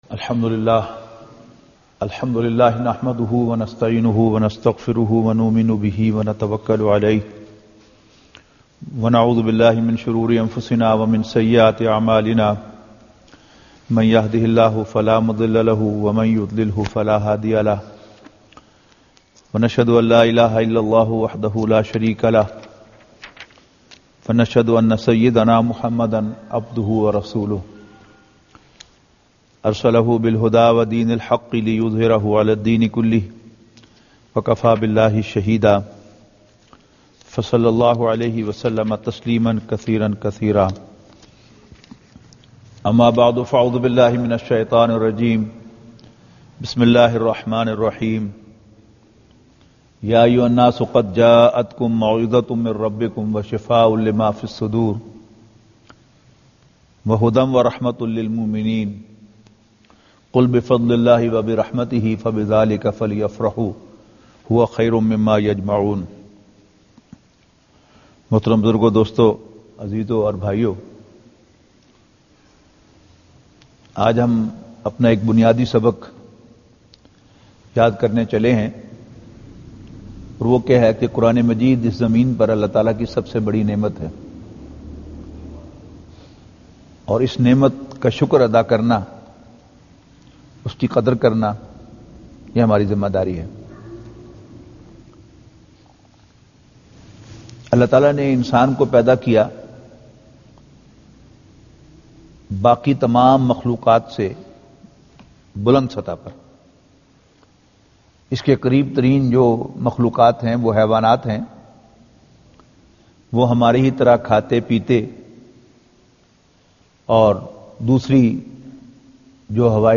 Khitab E Juma / Audio / 49 Quran Allah Ki Namit